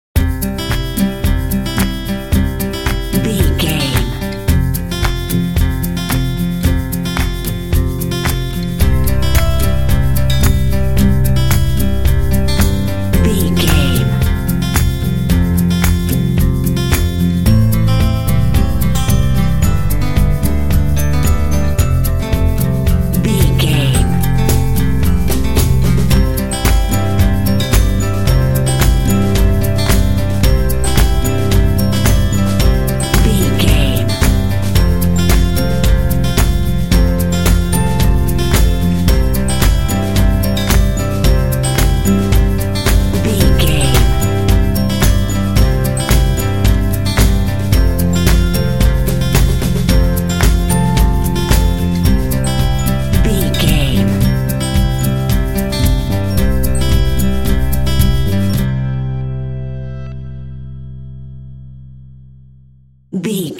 Uplifting
Ionian/Major
bright
joyful
acoustic guitar
bass guitar
drums
percussion
electric piano
indie
pop
contemporary underscore